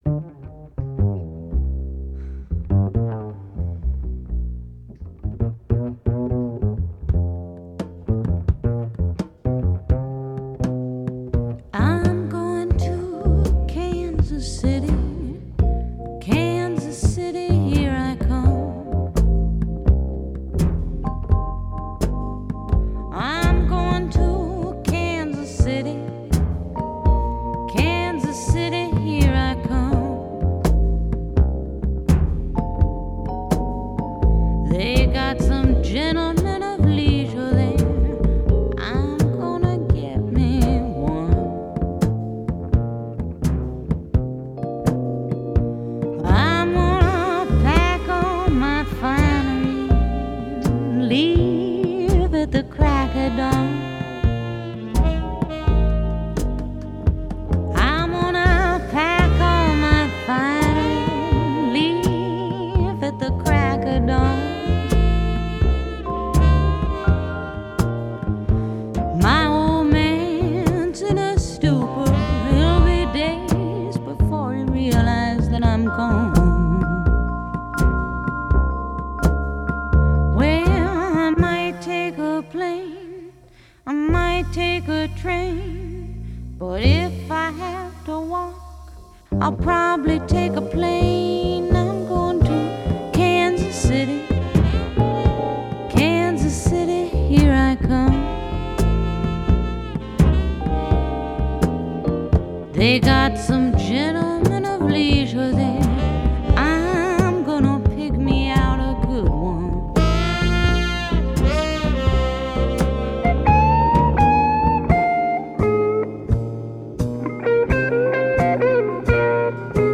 Genre: Pop, Folk, Rock